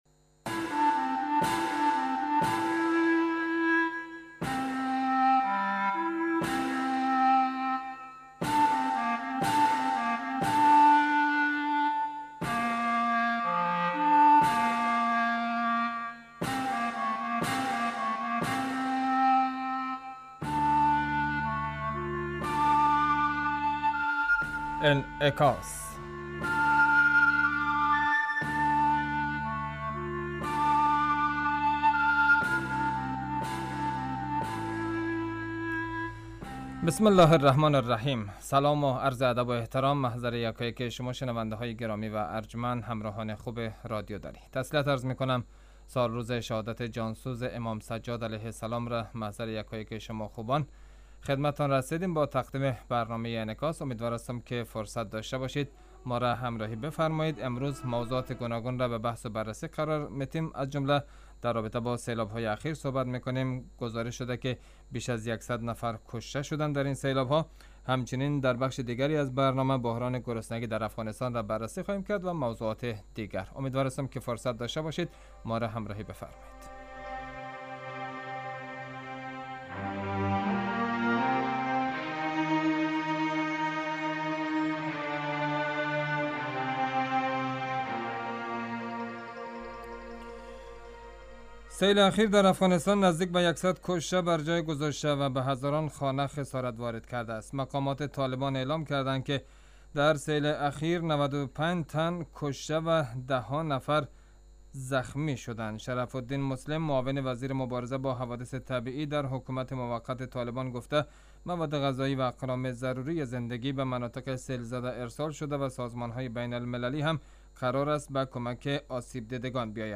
برنامه انعکاس به مدت 35 دقیقه هر روز در ساعت 18:50 بعد ظهر بصورت زنده پخش می شود.